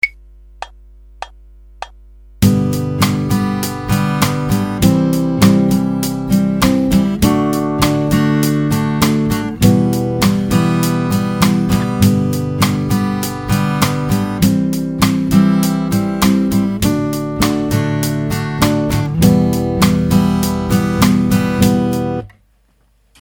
The first way is to simply strum chords with your thumb for a more muted sound in comparison to playing with a pick. Try the example below using the open string chords of G C D and E minor.
Thumb Strum | Download